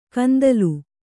♪ kandalu